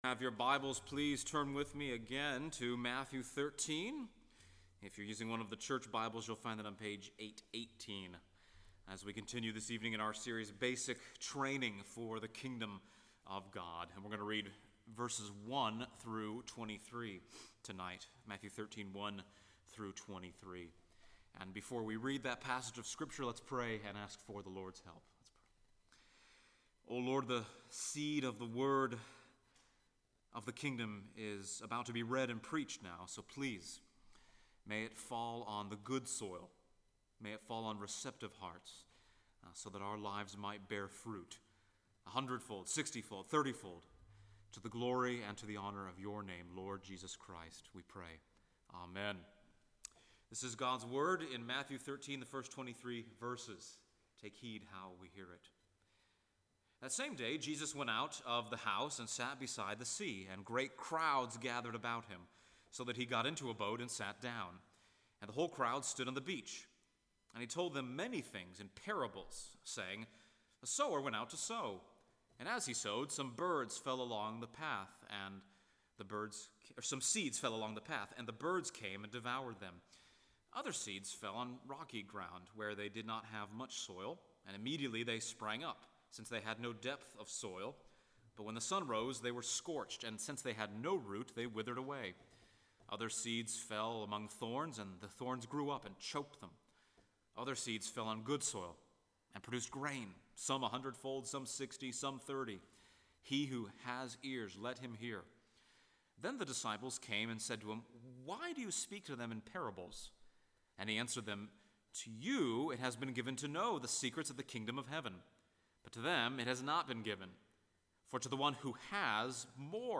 Matthew 13:1-52 Service Type: Sunday Evening %todo_render% « 2 John 1-3